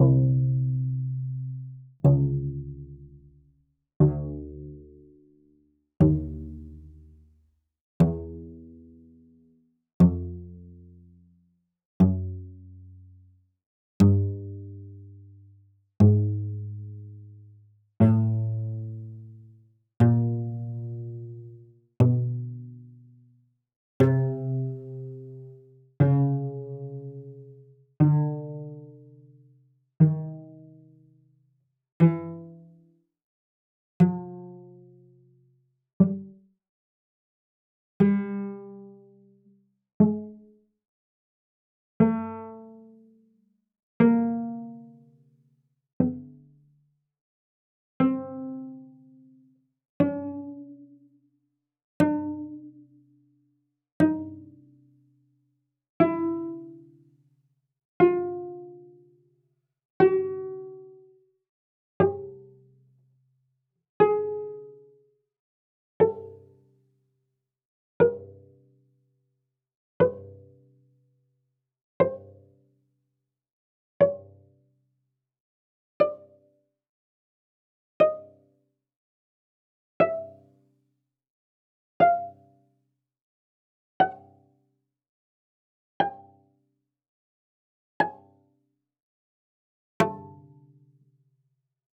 cello_uiowa_pizz_46.wav